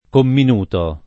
[ kommin 2 to ]